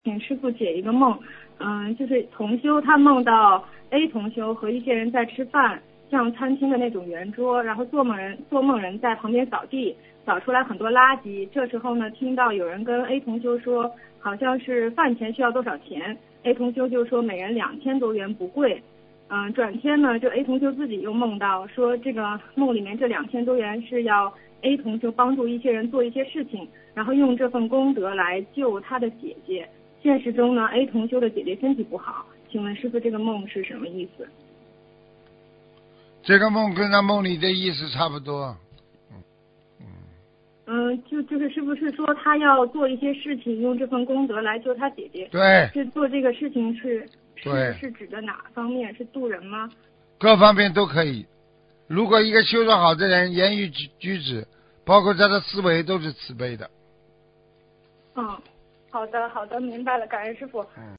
目录：2018年8月_剪辑电台节目录音_集锦